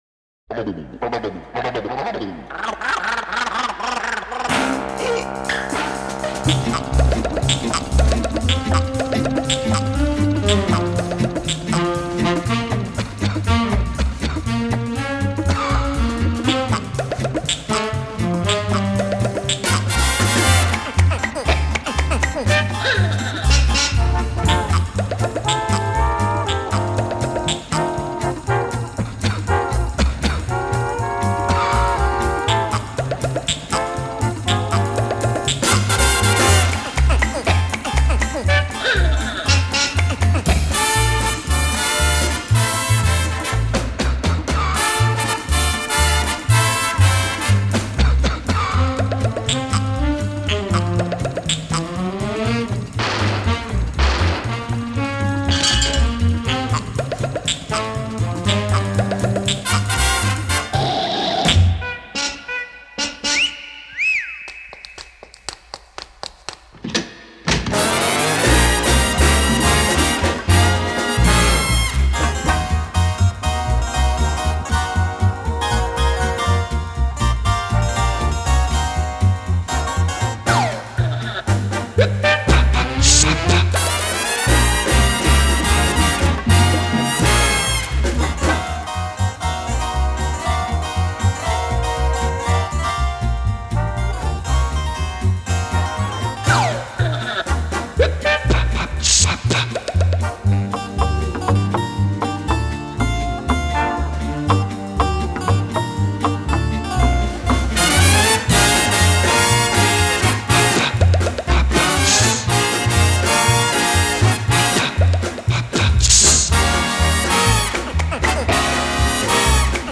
OLED SIIN ▶ muusika ▶ Lounge